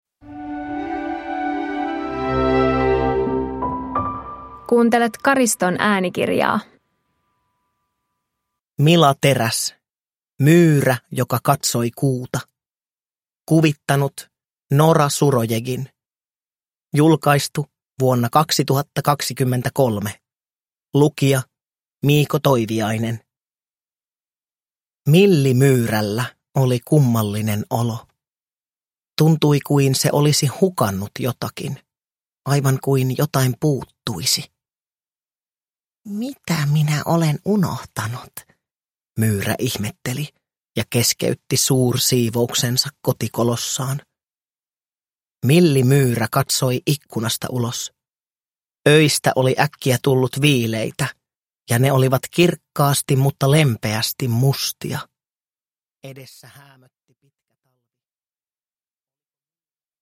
Myyrä joka katsoi kuuta – Ljudbok – Laddas ner